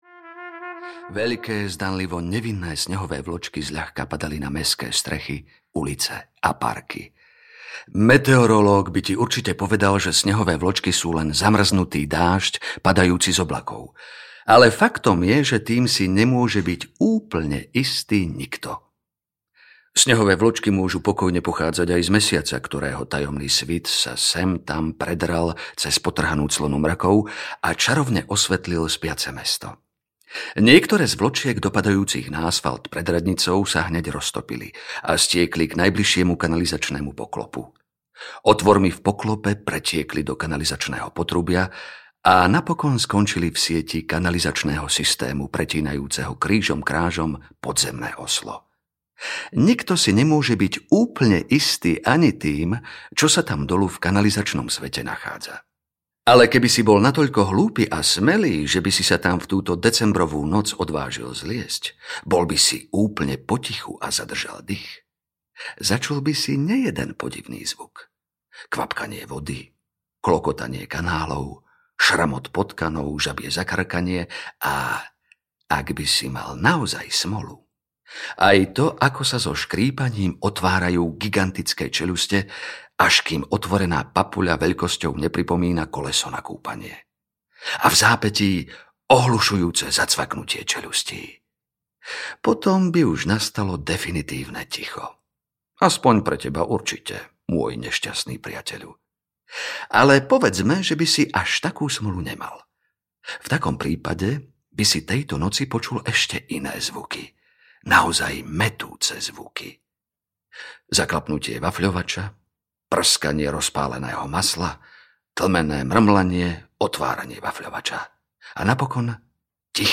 Doktor Proktor a koniec sveta. Možno. audiokniha
Ukázka z knihy